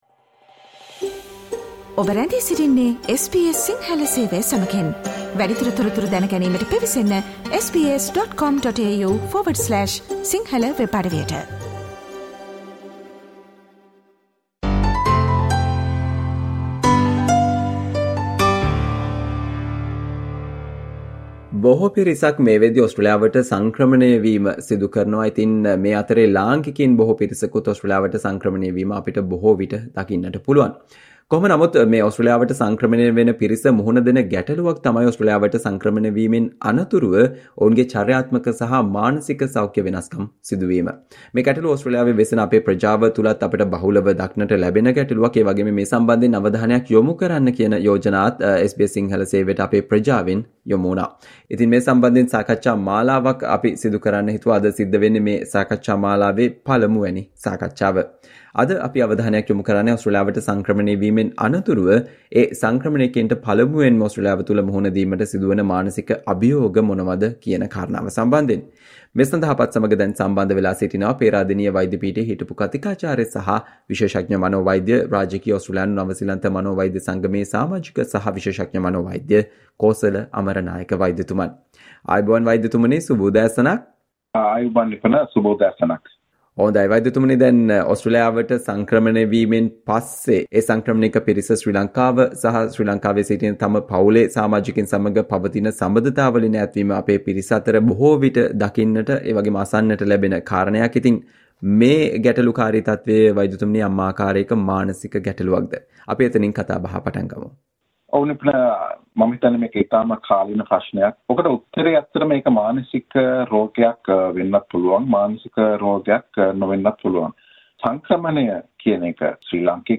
SBS Sinhala discussion on Why do many people forget their relatives and friends in Sri Lanka after coming to Australia?